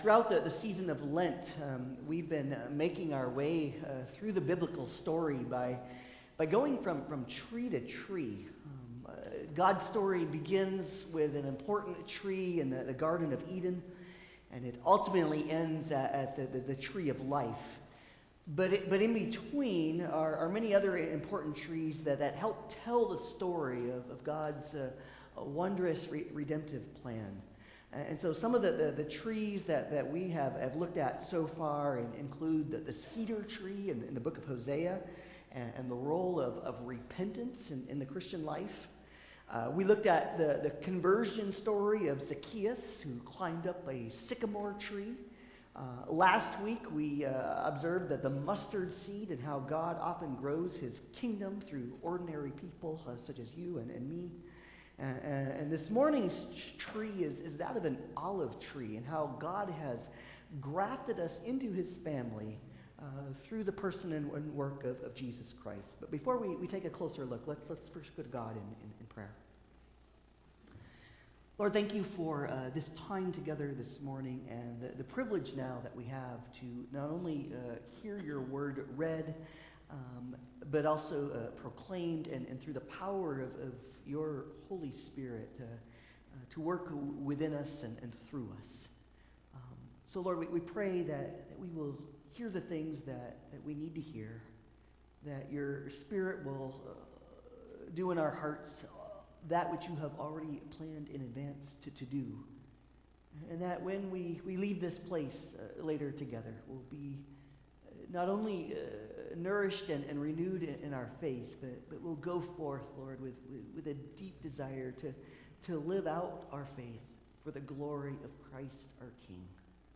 2023 Passage: Romans 11:13-24 Service Type: Sunday Service « The Kingdom of Heaven is Like a Mustard Seed…